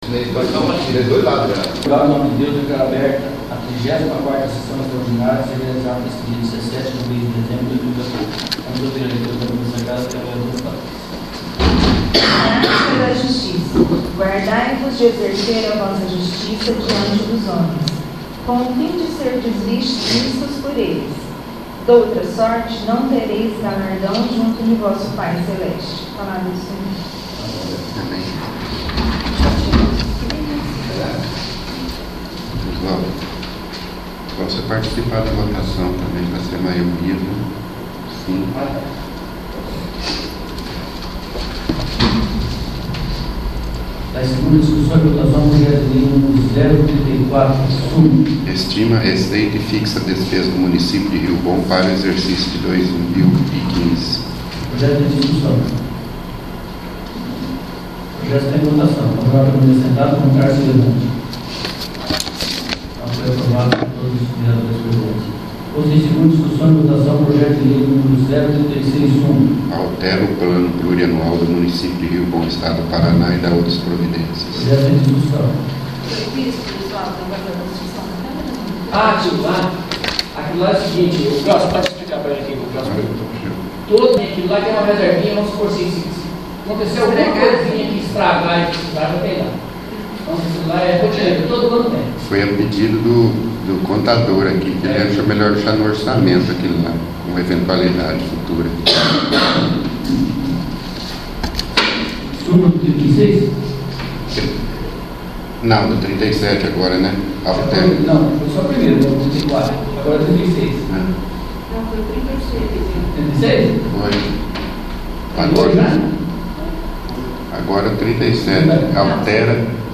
34º. Sessão Extraordinária